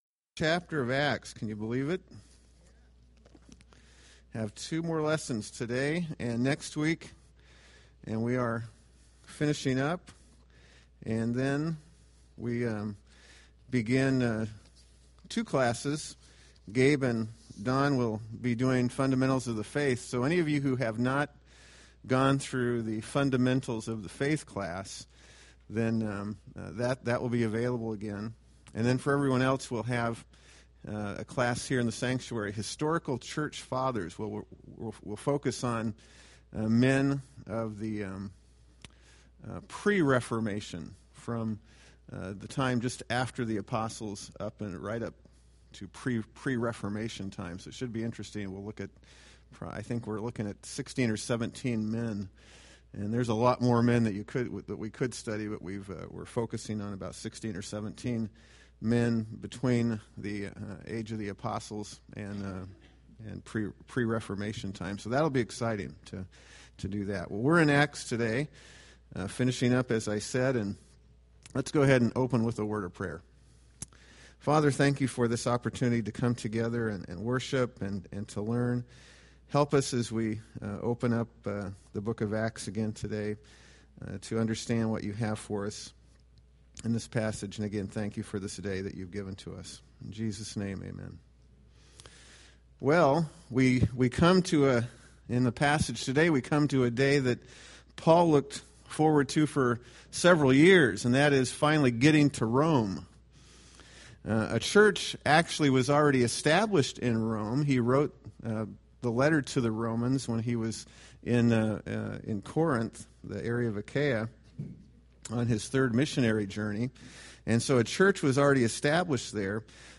Date: Jan 11, 2015 Series: Acts Grouping: Sunday School (Adult) More: Download MP3